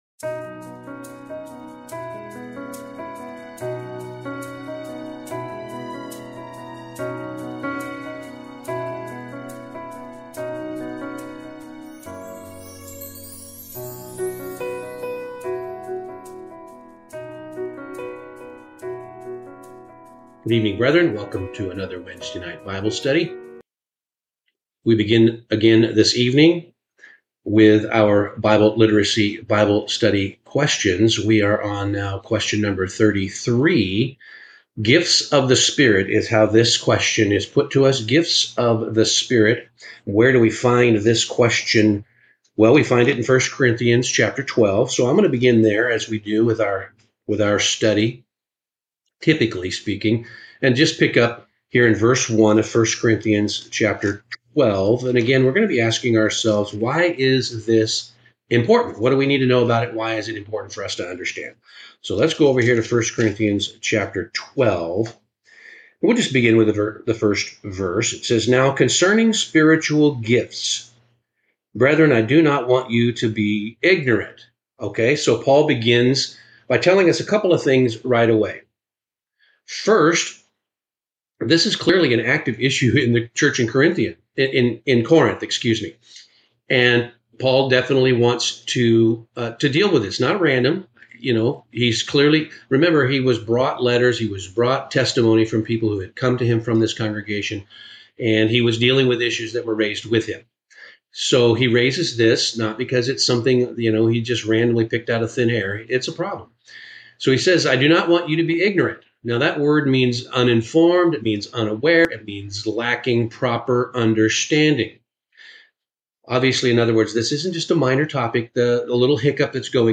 If you want to better understand how God designed His Church to function—and where you fit within it—listen to this bible study.